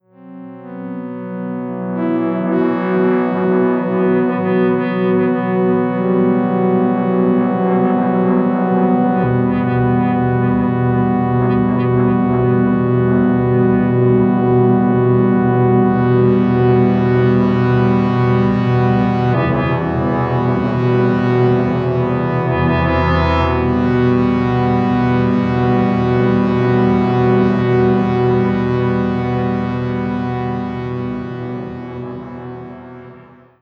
2_FXDistPad12.wav